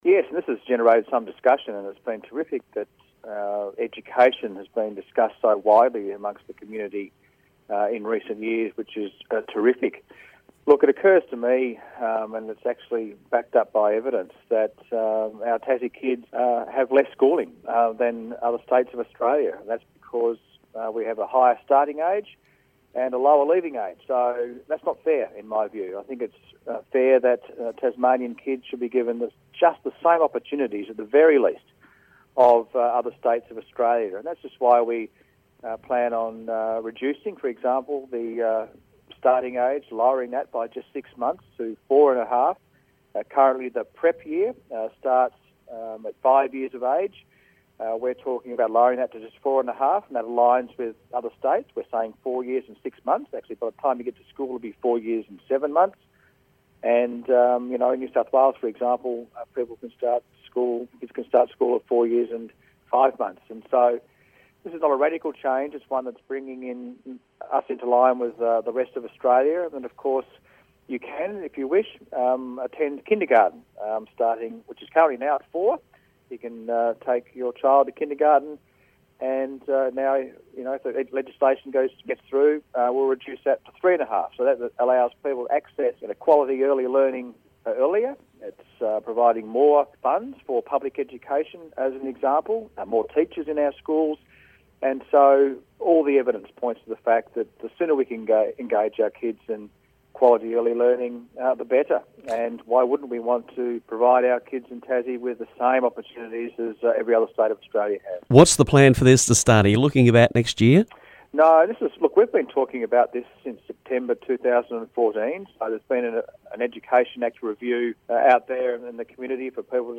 Education Minister Jeremy Rockliff explains the plan to lower the school starting age in Tassie